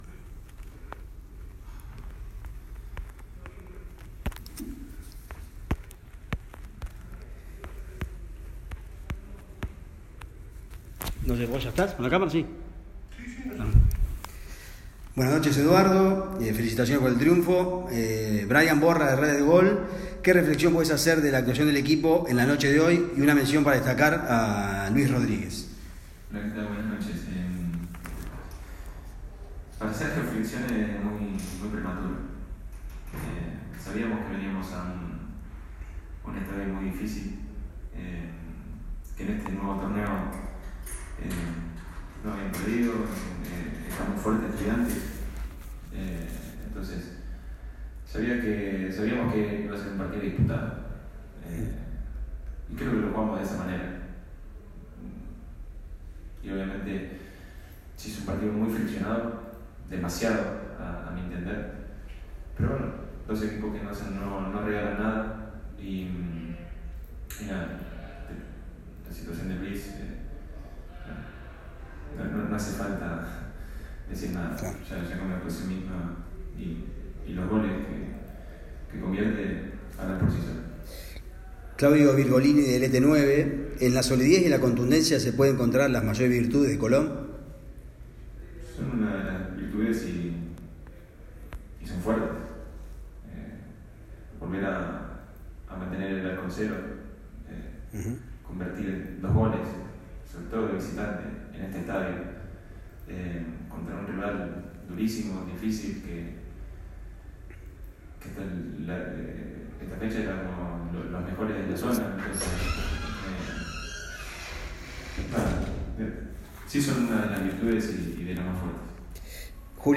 Escuchá la palabra del entrenador